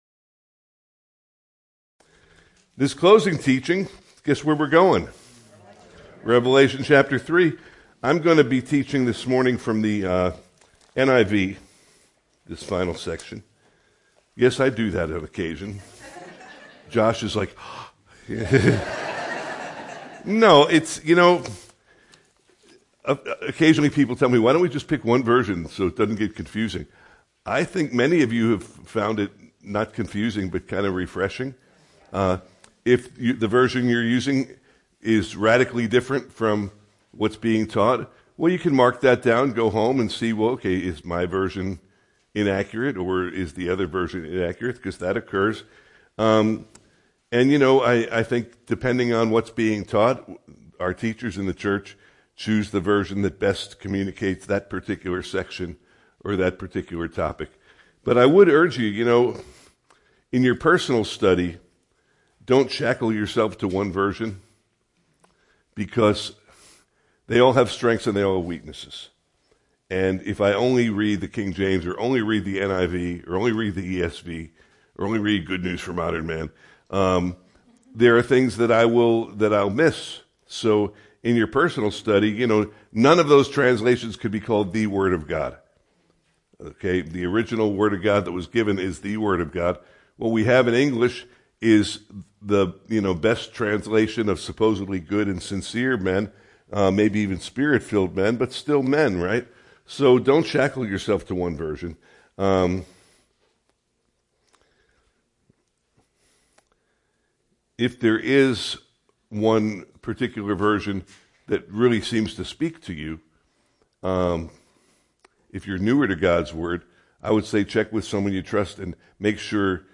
Part 6 of a series of verse-by-verse teachings on the opening chapters of the book of Revelation. There is great practical learning for us in the messages from our Lord to the churches.